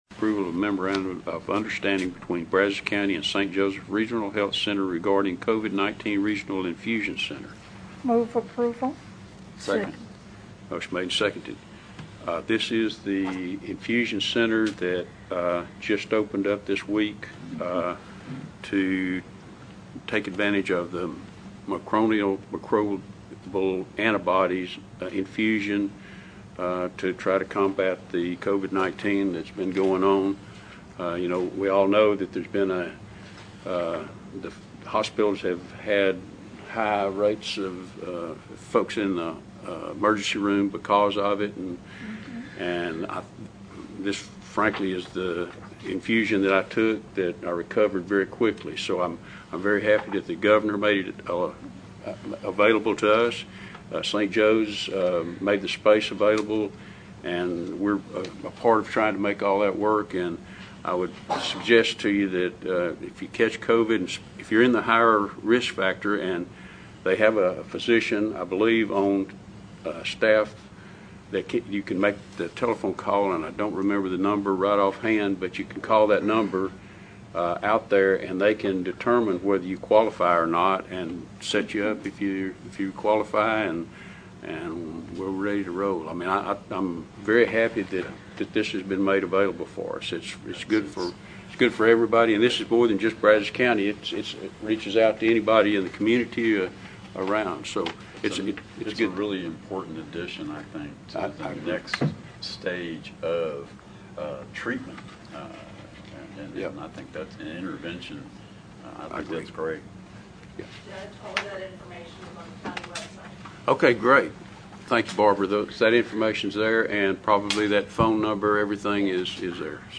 Click below for comments from the October 5, 2021 Brazos County commission meeting. Speakers began with Duane Peters.